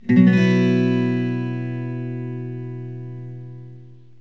Bsus4.mp3